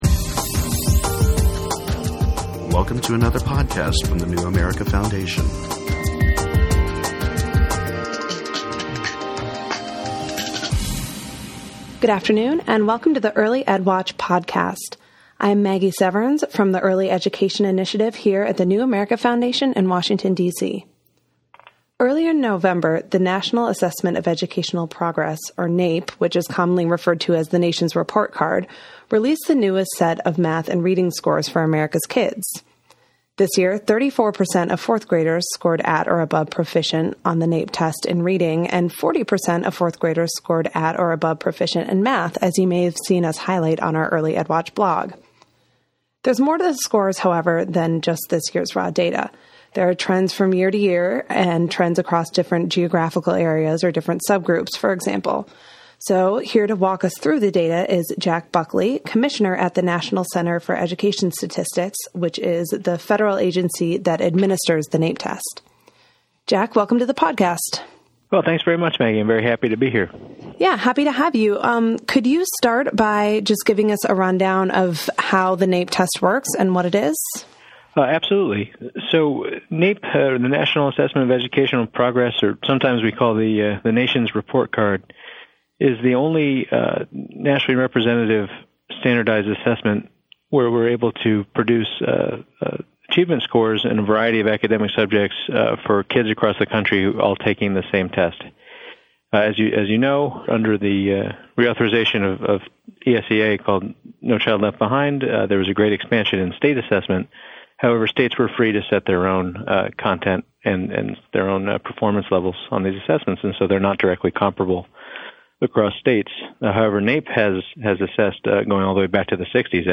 For this podcast, we spoke with Jack Buckley, commissioner at the National Center for Education Statistics, the center which administers the NAEP test. He took Early Ed Watch on a tour of the data from the most recent NAEP scores.